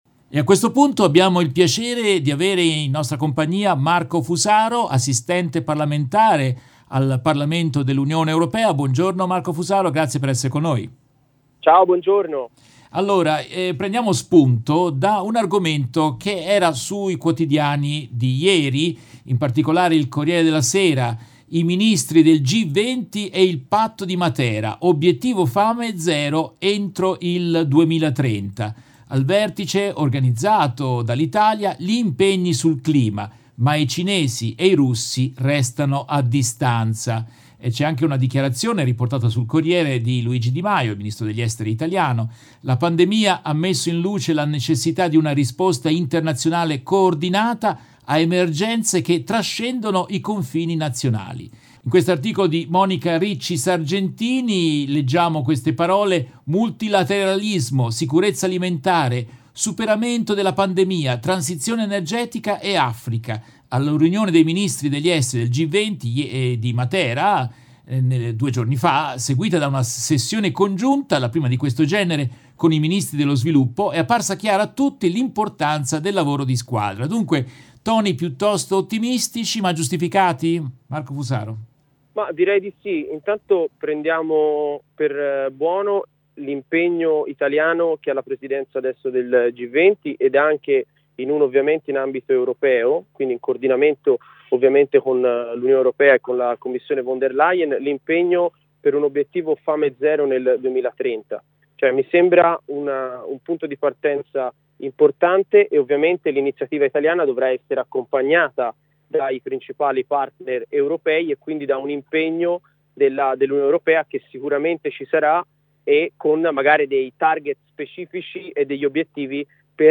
In questa intervista tratta dalla diretta RVS dell' 1 luglio 2021